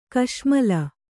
♪ kaśmala